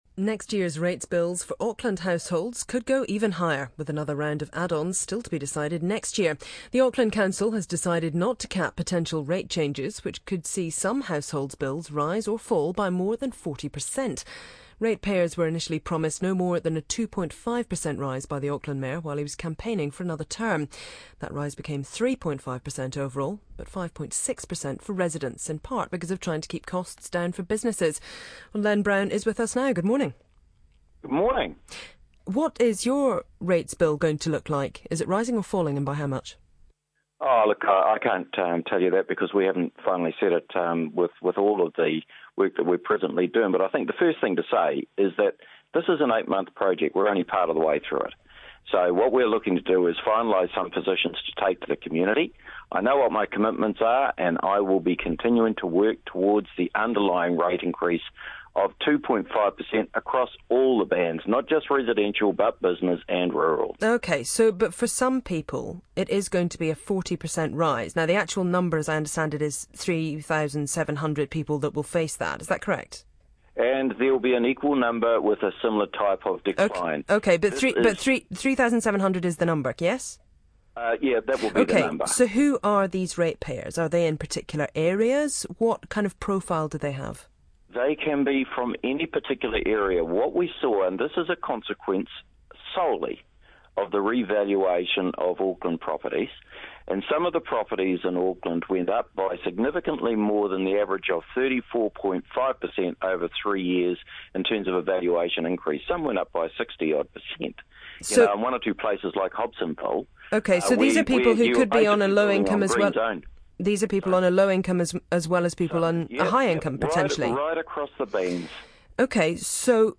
The Radio NZ Interview with Mayor Len Brown on the Rates situation